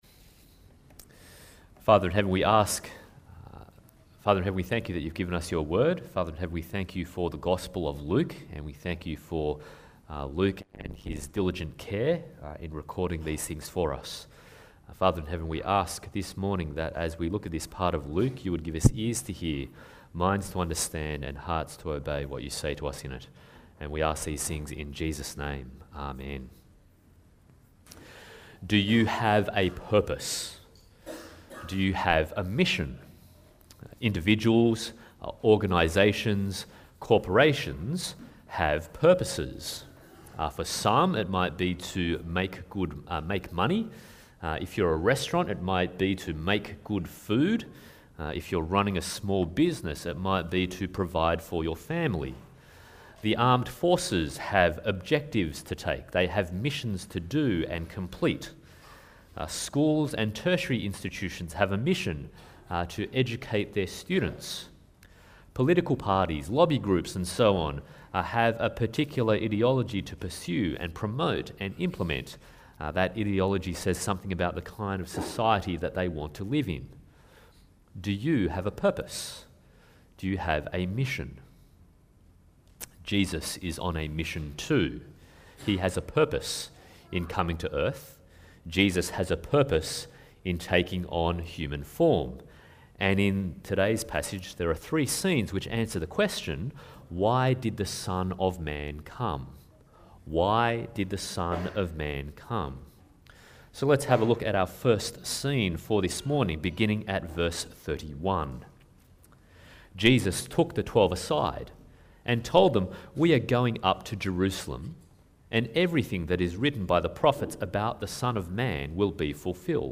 Luke 9-19 Passage: Luke 18:31-19:10 Service Type: Sunday Morning « Who Will Enter The Kingdom of God?